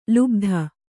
♪ lubdha